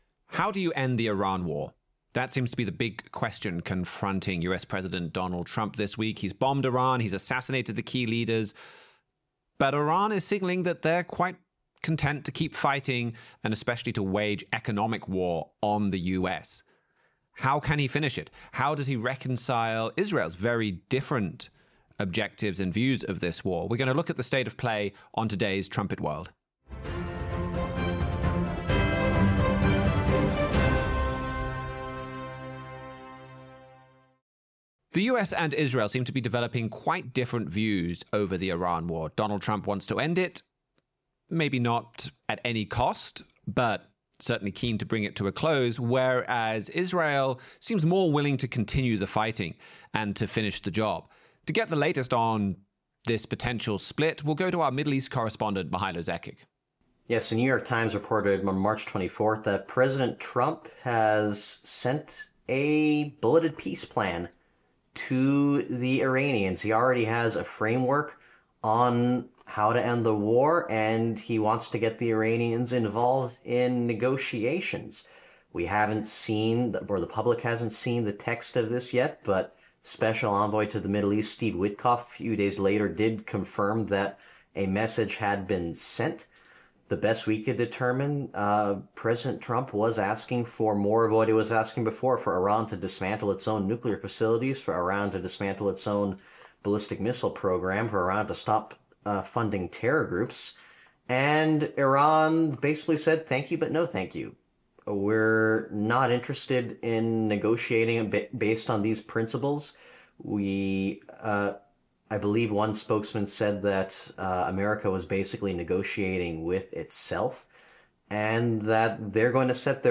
Join the discussion as Trumpet staff members compare recent news with Bible prophecy.